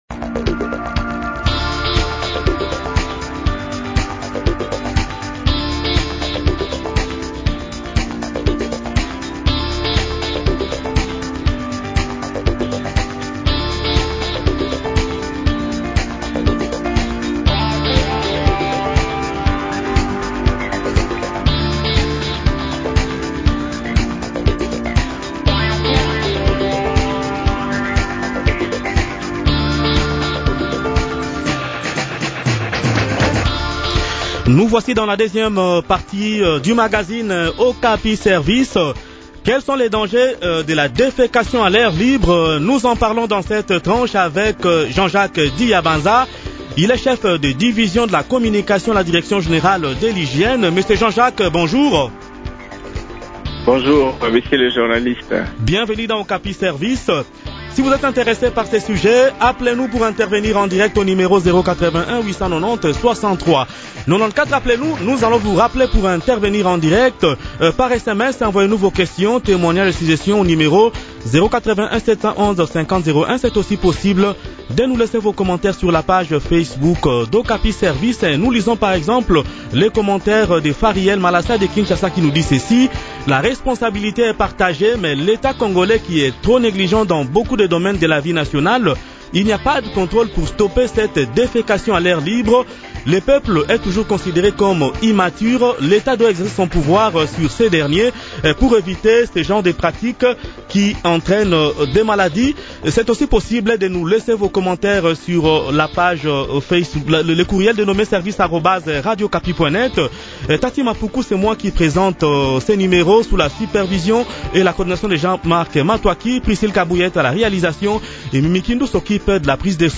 discute de ce sujet